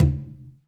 Tumba-HitN_v3_rr2_Sum.wav